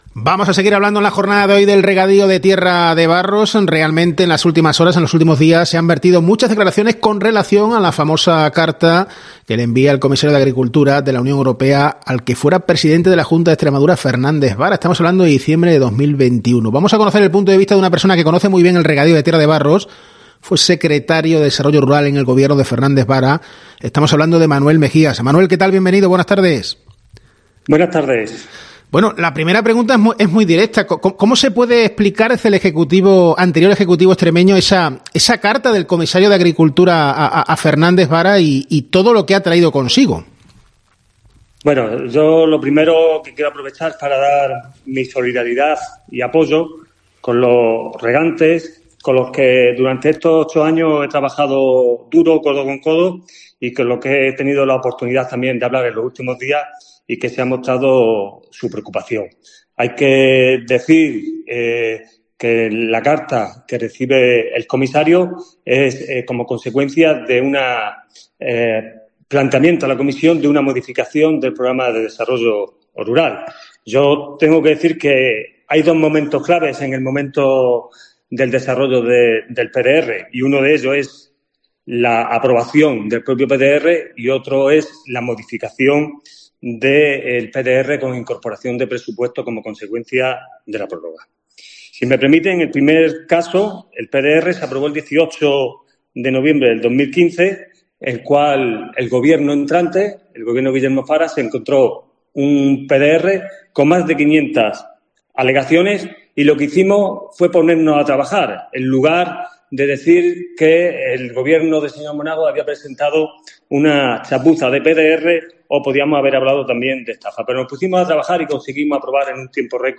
COPE Extremadura ha entrevistado al secretario general de Desarrollo Rural del Gobierno de Fernández Vara sobre el regadío de Tierra de Barros.